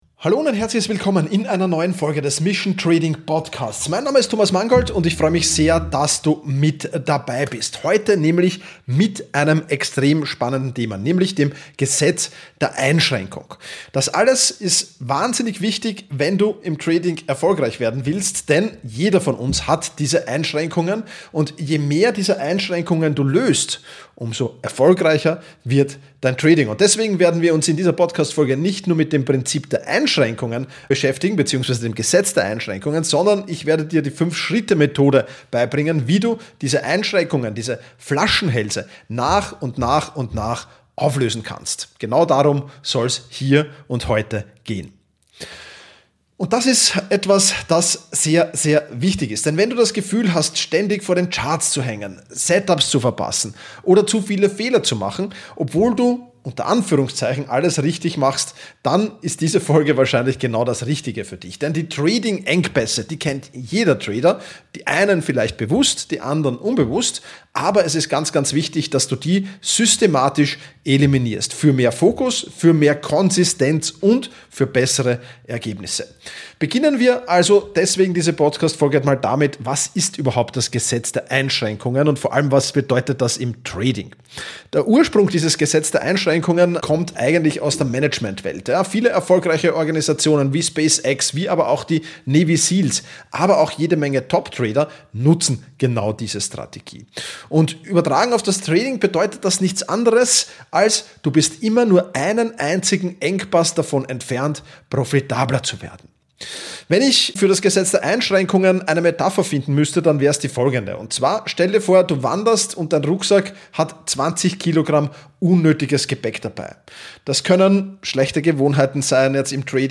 Meine Stimme wurde dafür geklont.